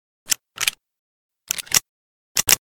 kiparis_reload_empty.ogg